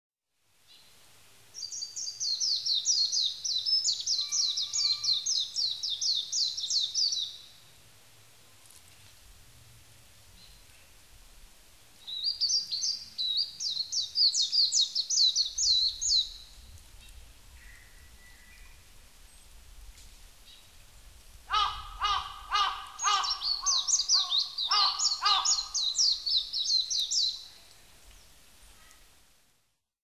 メジロ　Zosterops japonicusメジロ科
日光市稲荷川中流　alt=730m  HiFi --------------
0'55'' Windows Media Audio FILE MPEG Audio Layer3 FILE  Rec.: SONY TC-D5M
Mic.: audio-technica AT822
他の自然音：　 ハシブトガラス・キジ・ウグイス